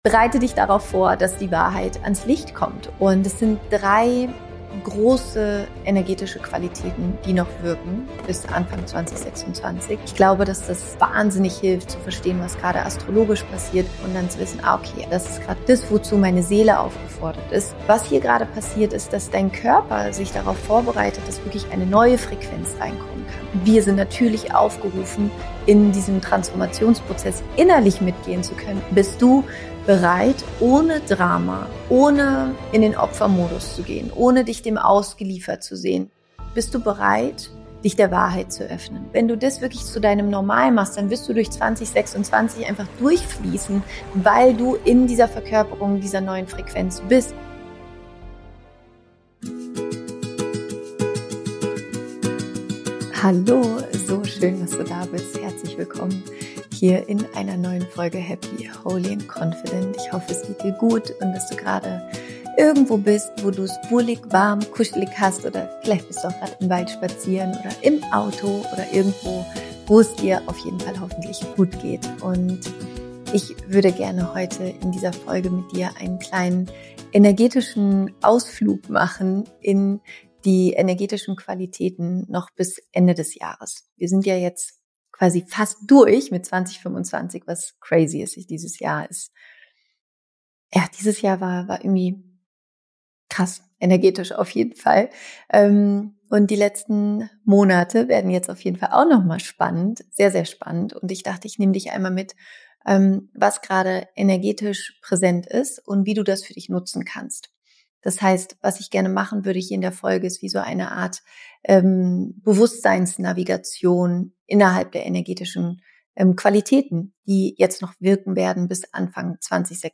In dieser besonderen Solofolge nehme ich dich mit auf eine energetische Navigation für den Rest des Jahres 2025.